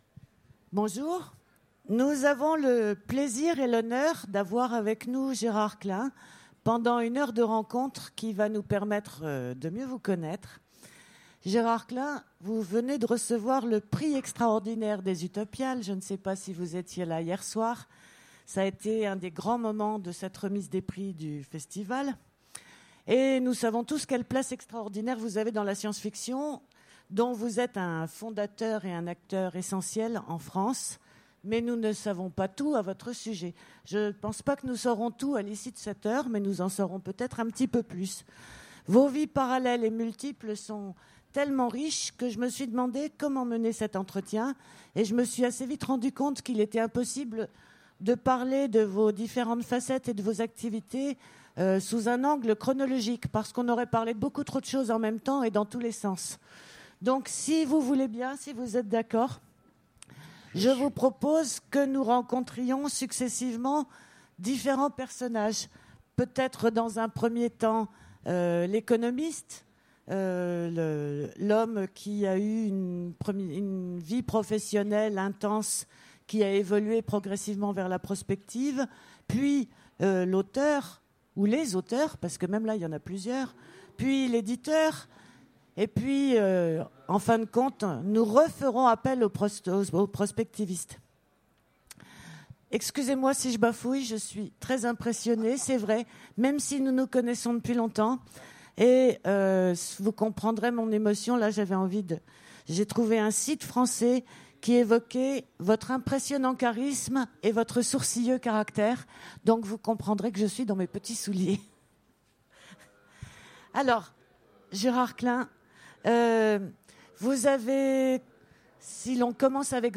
- le 31/10/2017 Partager Commenter Utopiales 2016 : Rencontre avec Gérard Klein Télécharger le MP3 à lire aussi Gérard Klein Genres / Mots-clés Rencontre avec un auteur Conférence Partager cet article